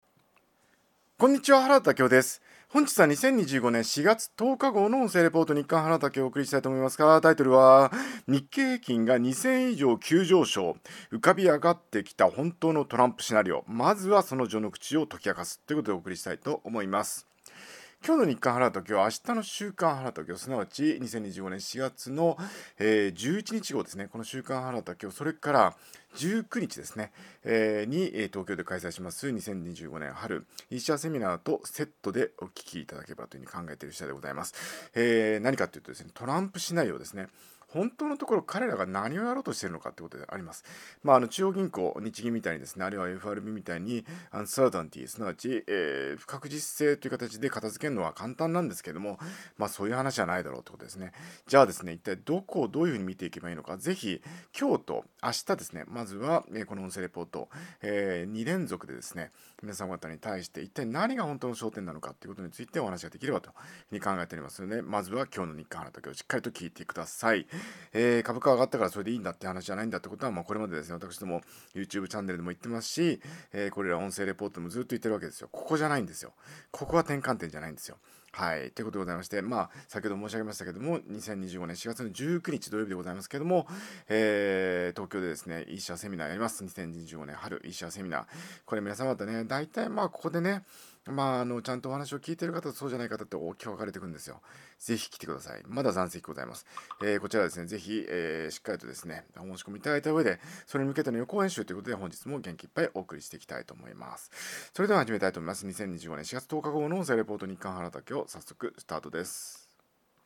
音声レポート